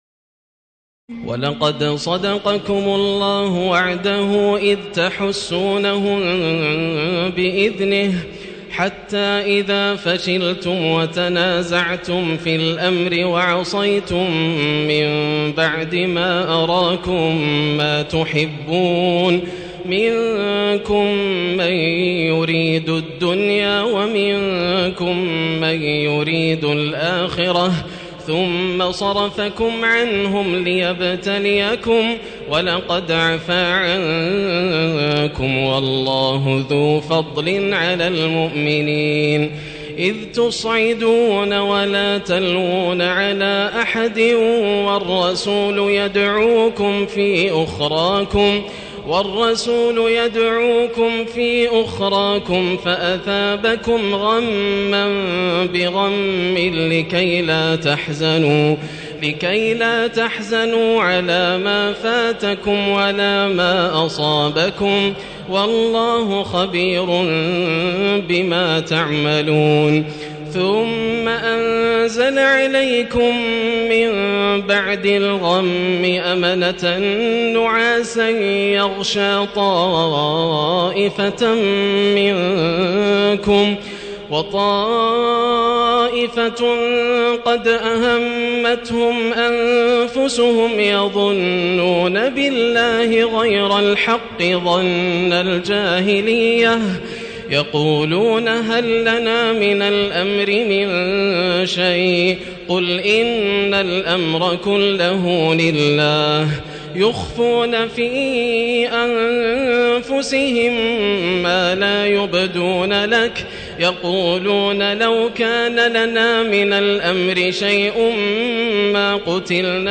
تراويح الليلة الرابعة رمضان 1437هـ من سورتي آل عمران (152-200) و النساء (1-18) Taraweeh 4 st night Ramadan 1437H from Surah Aal-i-Imraan and An-Nisaa > تراويح الحرم المكي عام 1437 🕋 > التراويح - تلاوات الحرمين